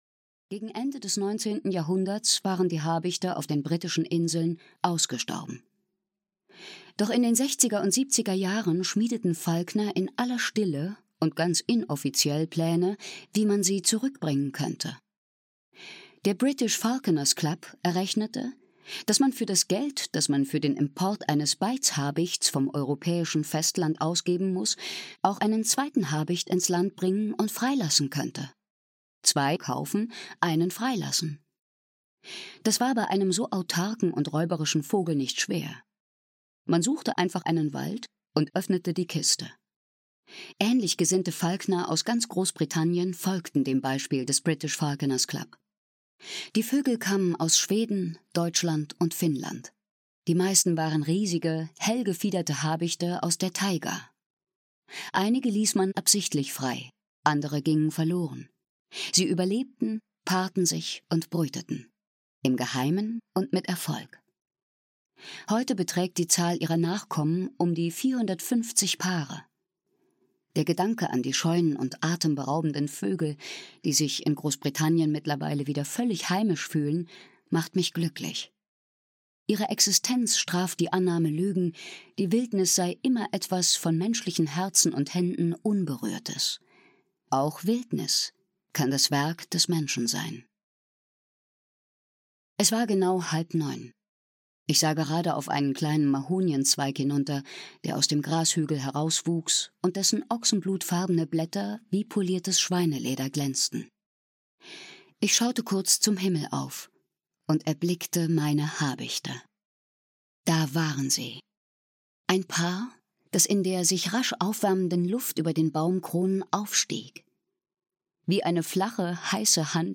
H wie Habicht - Helen Macdonald - Hörbuch